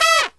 FALL HIT01-R.wav